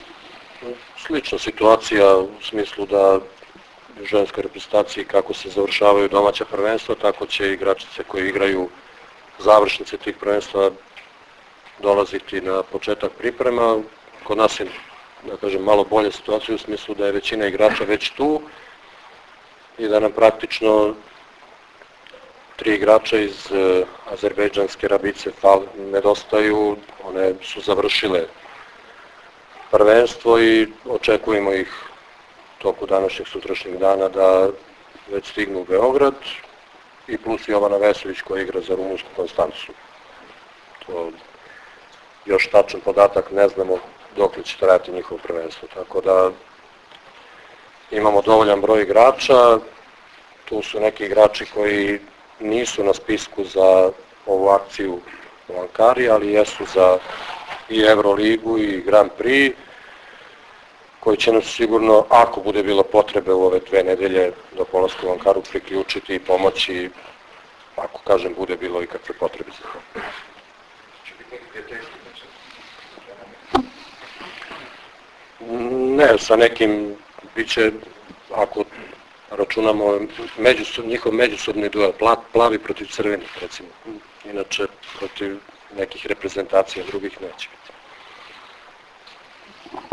IZJAVA ZORANA TERZIĆA 2